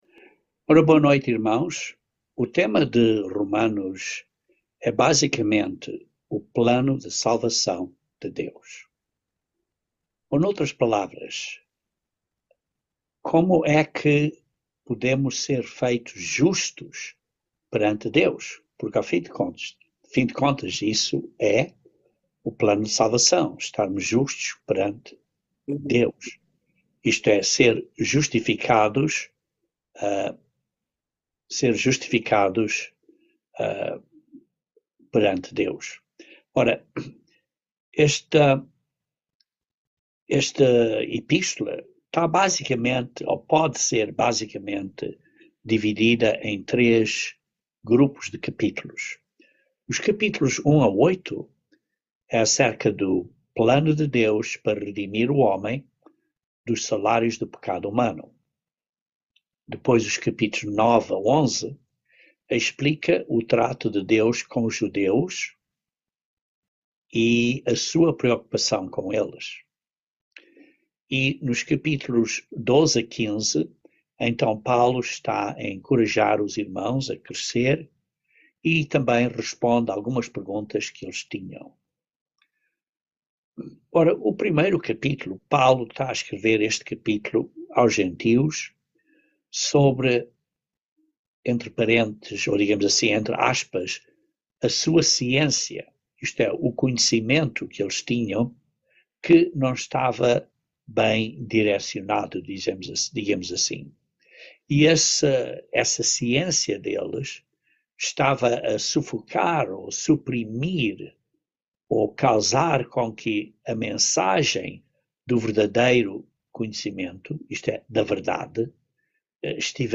Estudo Bíblico
Given in Patos de Minas, MG